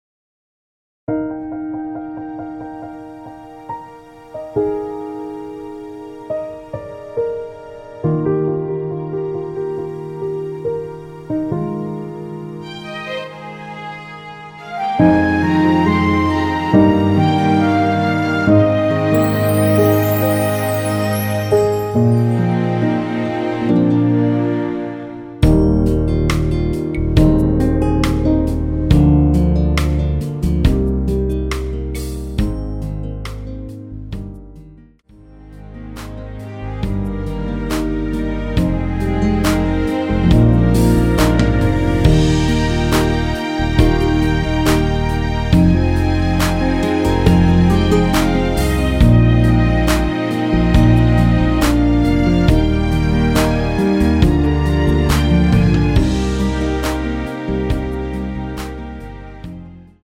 원키에서(-1)내린 MR입니다.
Eb
◈ 곡명 옆 (-1)은 반음 내림, (+1)은 반음 올림 입니다.
앞부분30초, 뒷부분30초씩 편집해서 올려 드리고 있습니다.
중간에 음이 끈어지고 다시 나오는 이유는